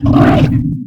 attack2.ogg